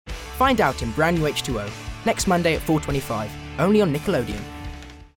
Fresh, Bright Young Voice. Great Narration with Likeable Tones
Promo, Bright, Friendly, Upbeat
RP ('Received Pronunciation')